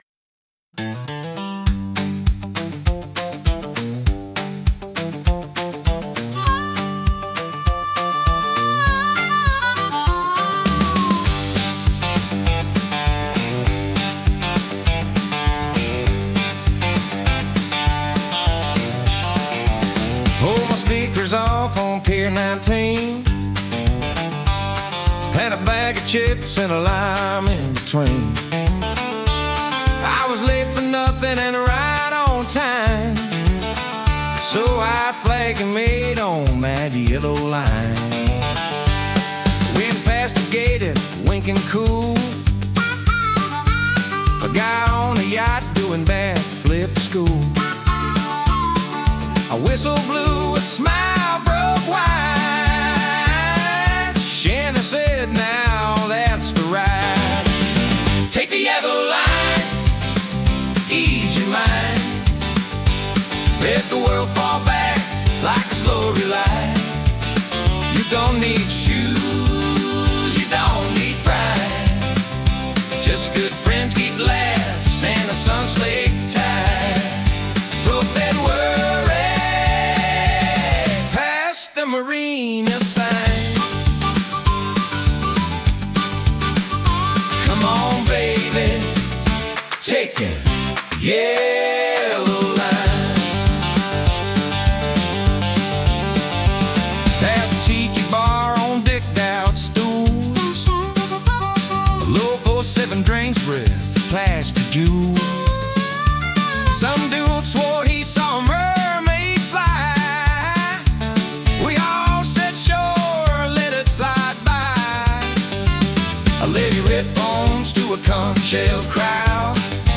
Waterway Blues